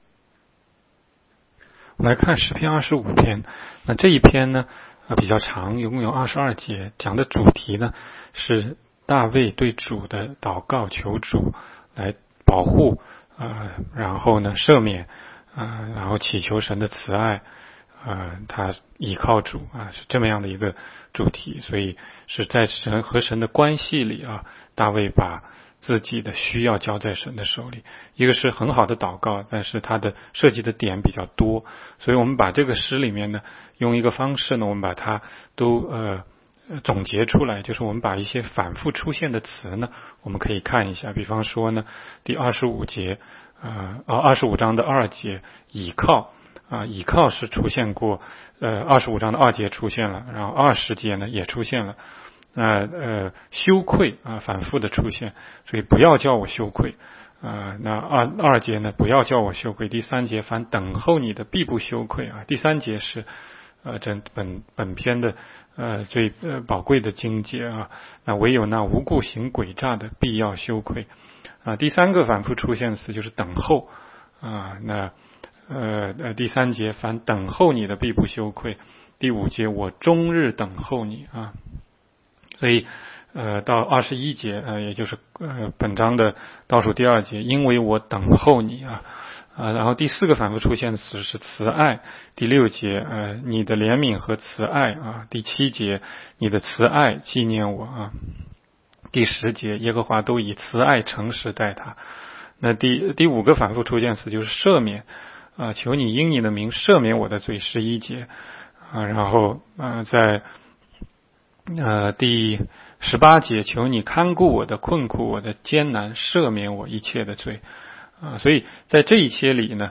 16街讲道录音 - 每日读经-《诗篇》25章